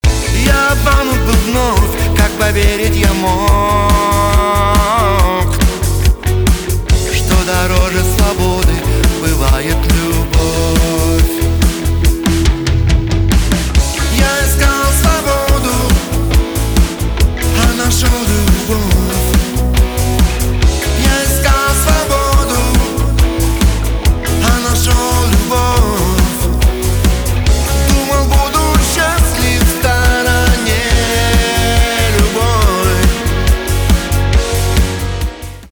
русский рок
гитара , барабаны